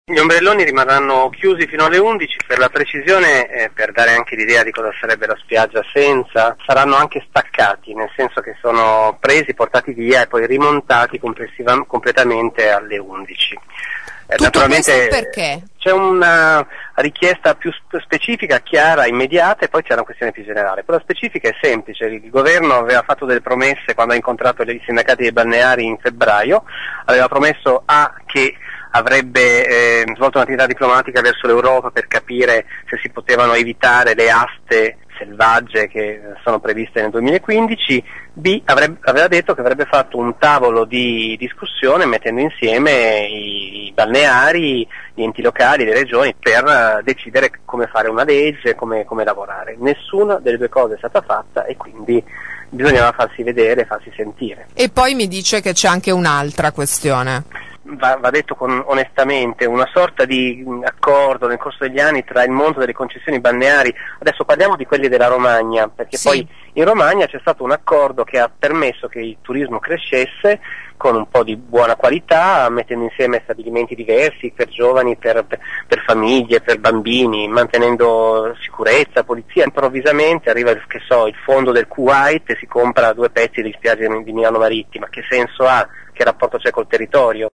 INTERV01.mp3